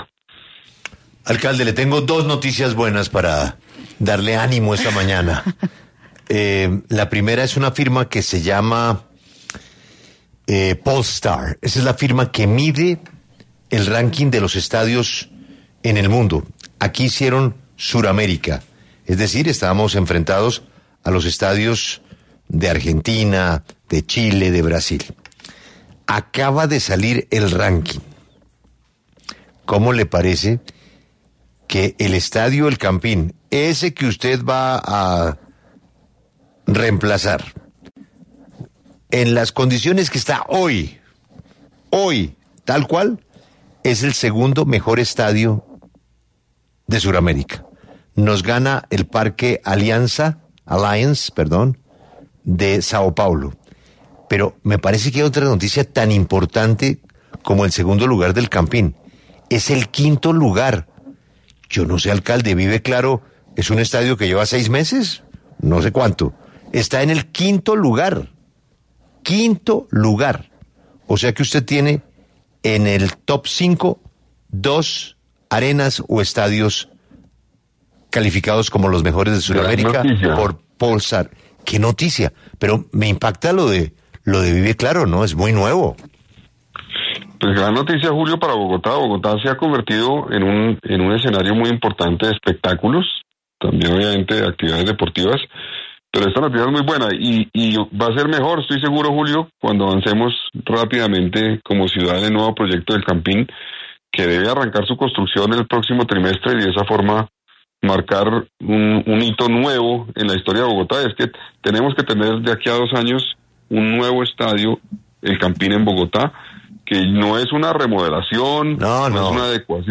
Ante esta noticia, el alcalde de Bogotá, Carlos Fernando Galán, habló en los micrófonos de 6AM W, con Julio Sánchez Cristo, sobre está medición.